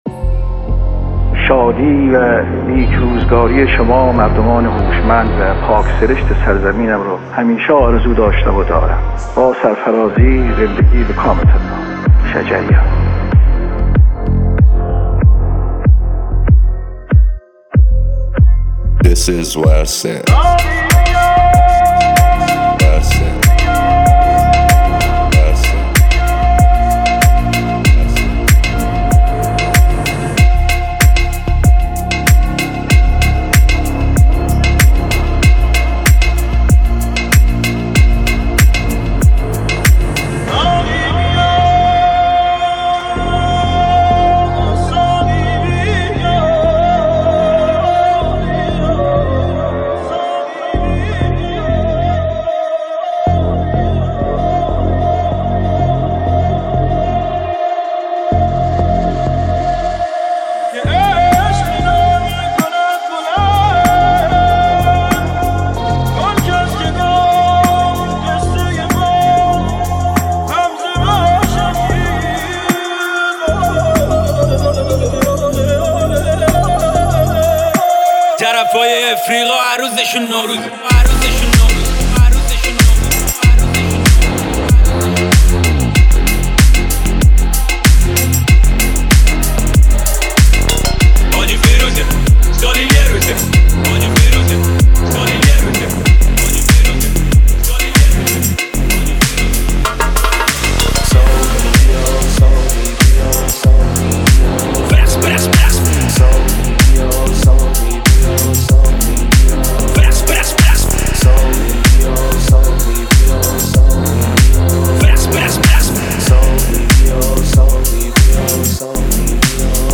ریمیکس اول